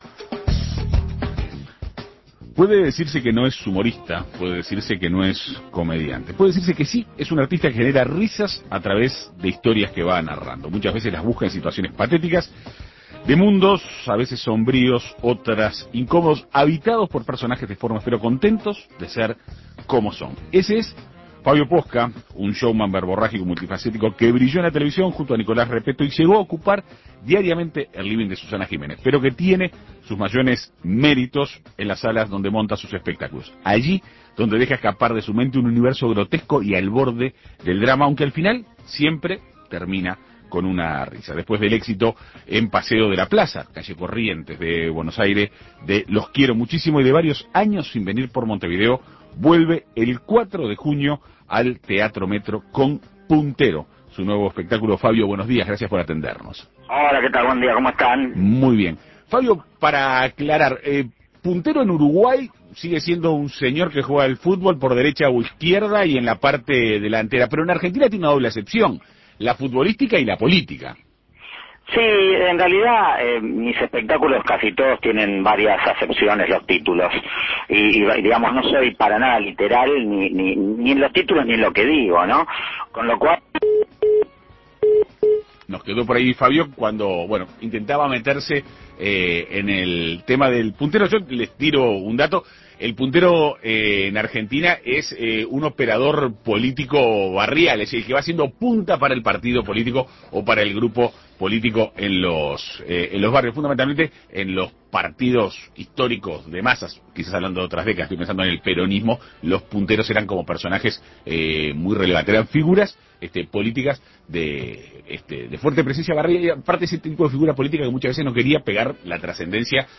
Después del éxito de los "Los quiero Muchísimo", vuelve el 4 de junio al Teatro Metro con "Puntero". En Perspectiva Segunda Mañana dialogó con Posca sobre los detalles de su futura presentación.